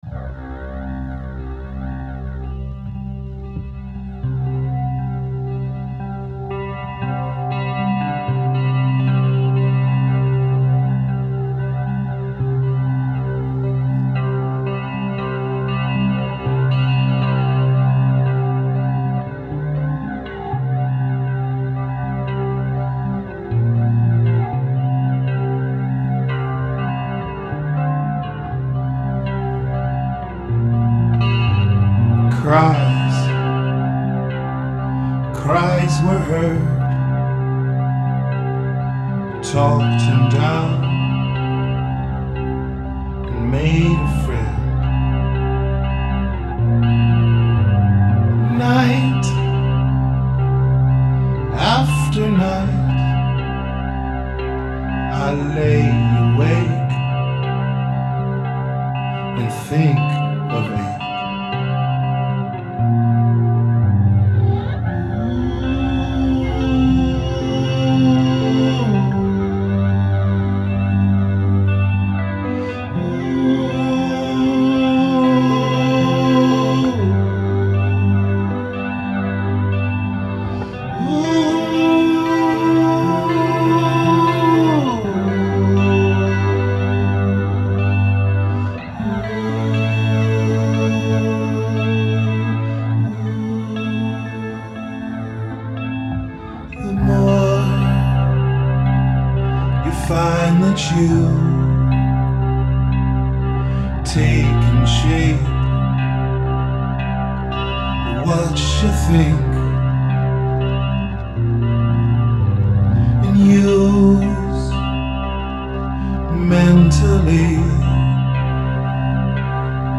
Rehearsals 27.2.2012